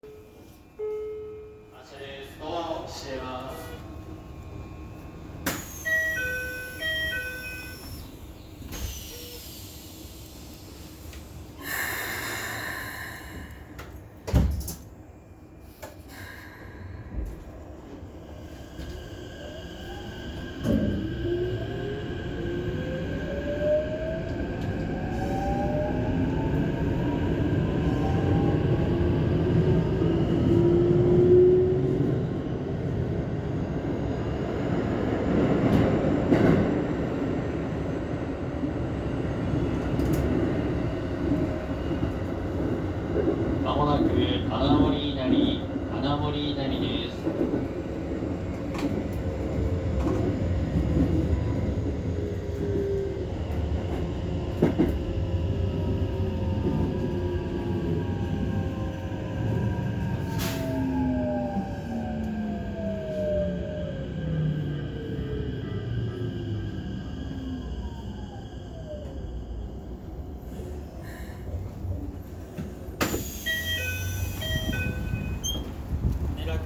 ・1000形（三菱SiC）走行音
【空港線】大鳥居→穴守稲荷